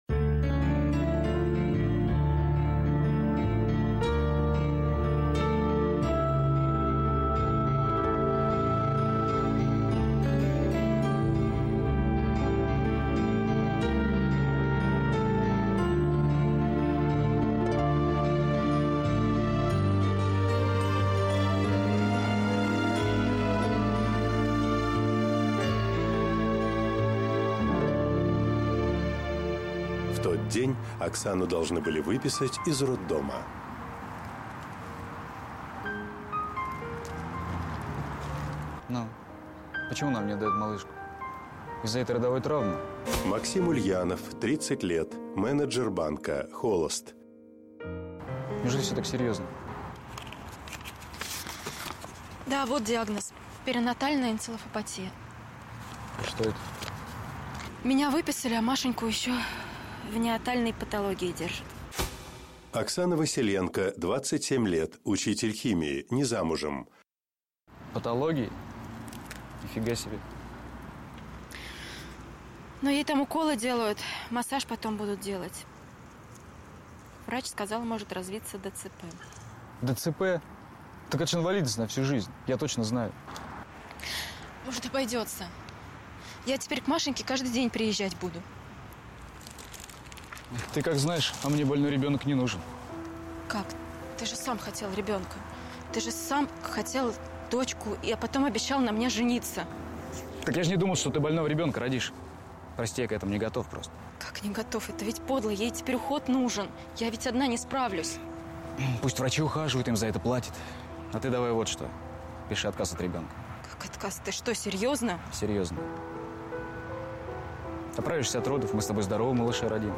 Аудиокнига Дедушка моей дочки | Библиотека аудиокниг
Прослушать и бесплатно скачать фрагмент аудиокниги